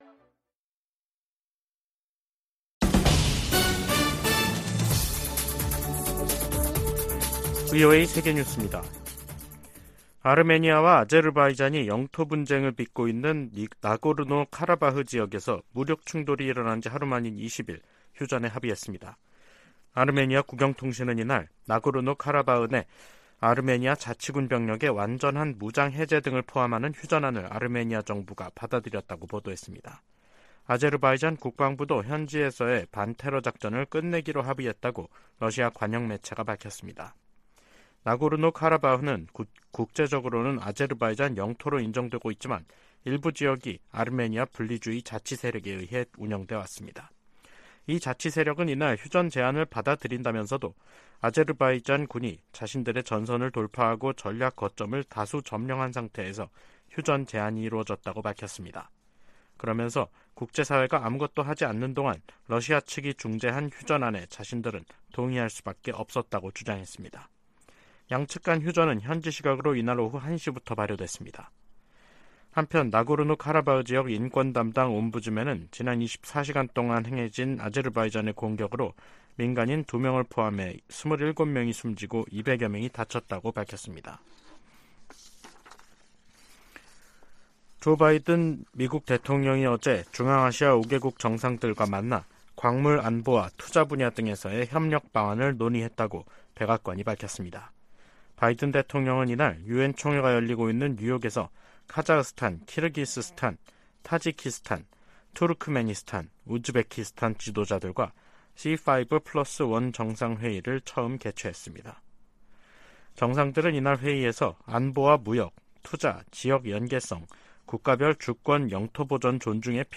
VOA 한국어 간판 뉴스 프로그램 '뉴스 투데이', 2023년 9월 20일 3부 방송입니다. 조 바이든 미국 대통령이 유엔총회 연설에서 북한 정권의 거듭된 유엔 안보리 결의 위반을 규탄했습니다. 한국 정부가 러시아 대사를 초치해 북한과의 군사 협력 움직임에 대해 강력히 경고했습니다. 러시아가 북한 같은 나라에 의존할 수밖에 없을 정도로 고립됐다고 로이드 오스틴 미 국방장관이 지적했습니다.